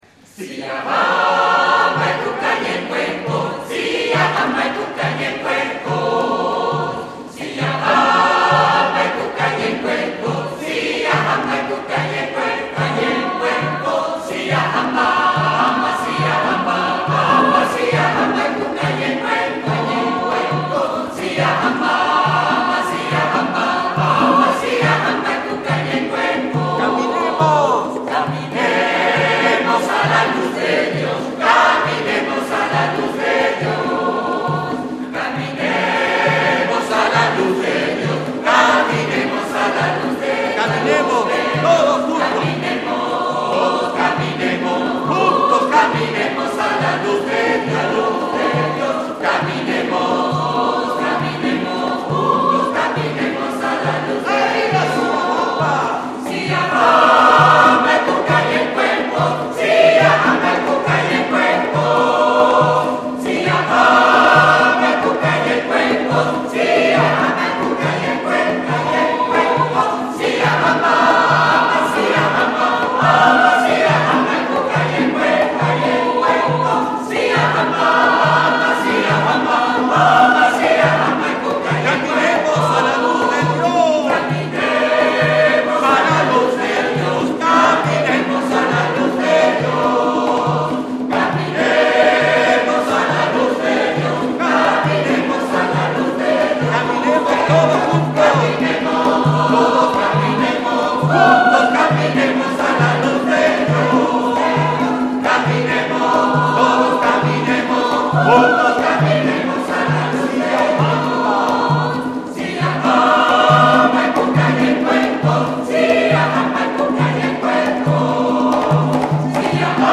grabación en vivo